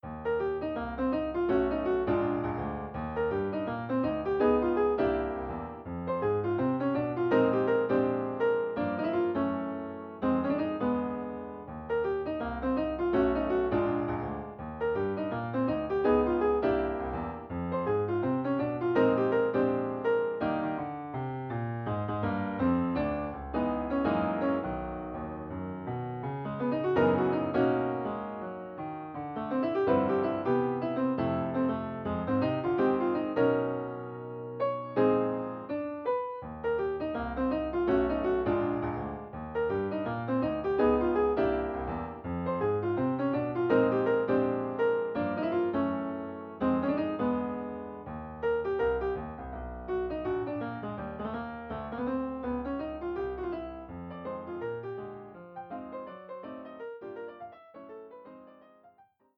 jazzy in character